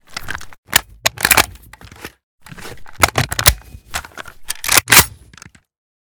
svd_reload_empty.ogg